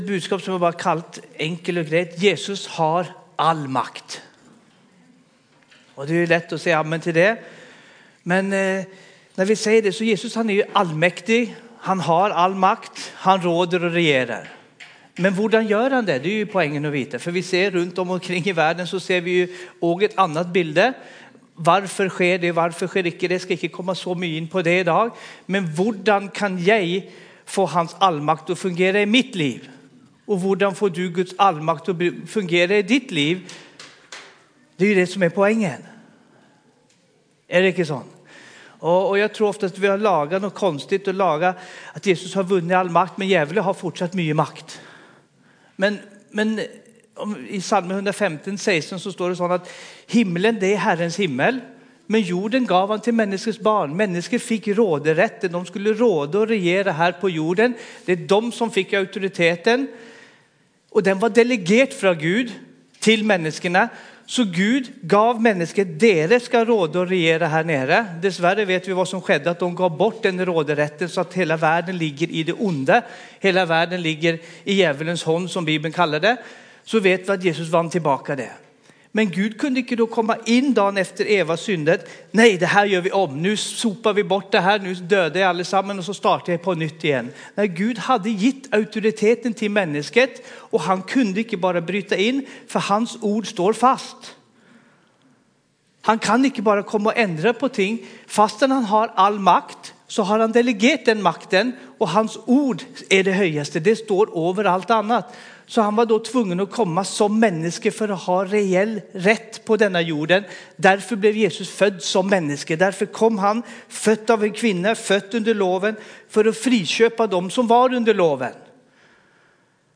Gudstjenester